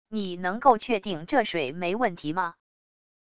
你能够确定这水没问题吗wav音频生成系统WAV Audio Player